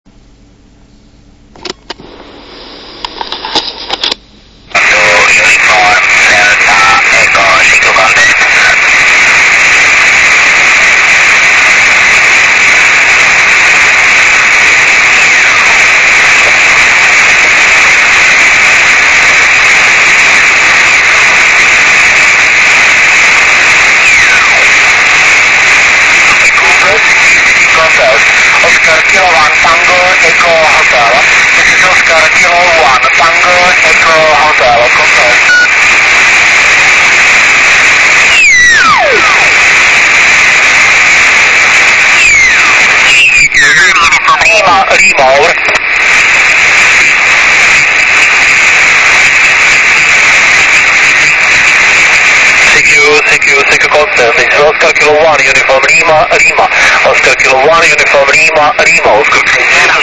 Jak poslouch� FT817 Na 432 MHz z Je�těda s předzesilovačem?
Uk�zky jsou z provozn�ho aktivu 19.5.2002..